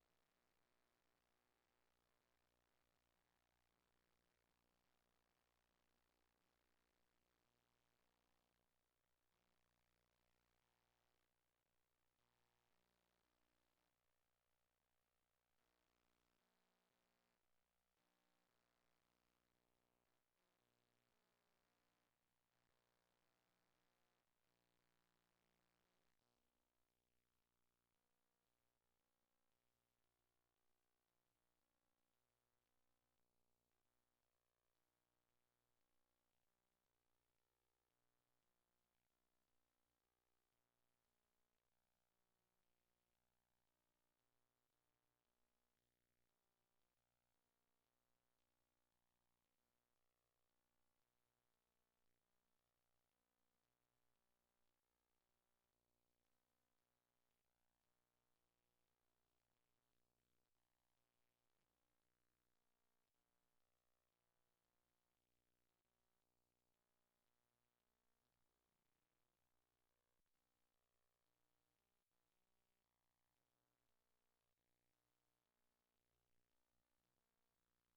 almostsilent.wav